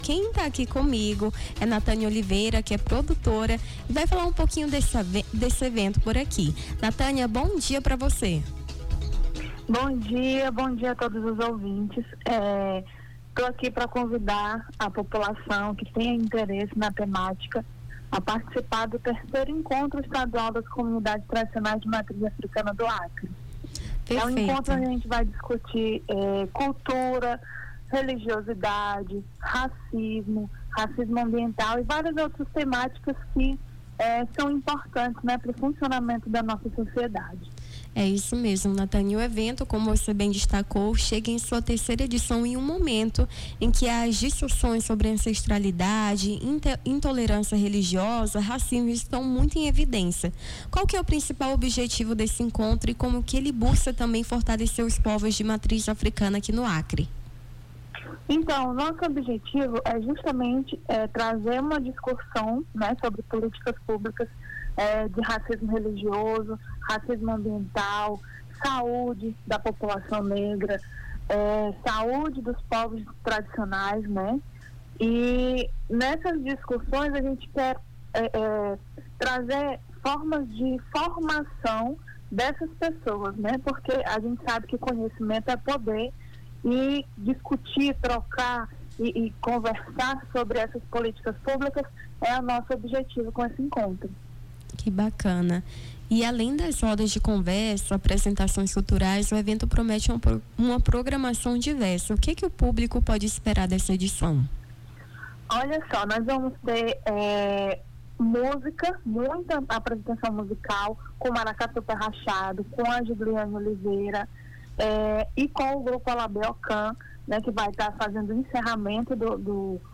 Nome do Artista - CENSURA - ENTREVISTA (ENCONTRO POVOS TRADICIONAIS) 18-07-25.mp3